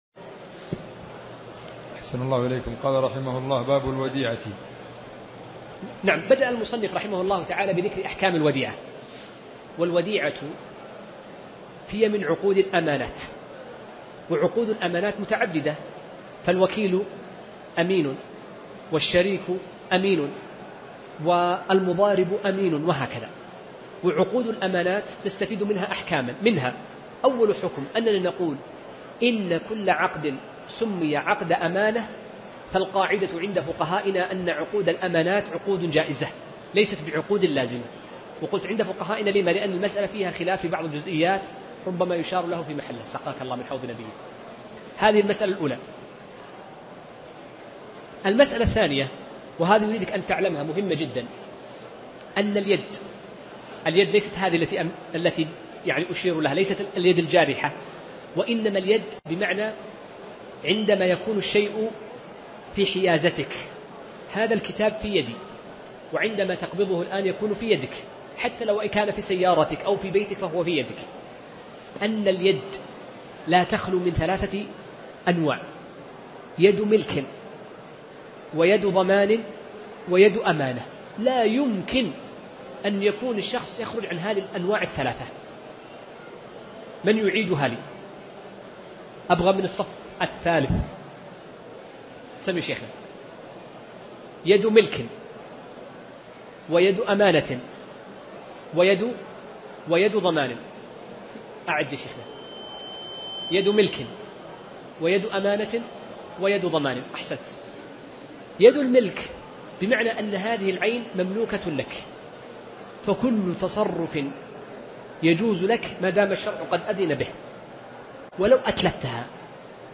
الدرس 53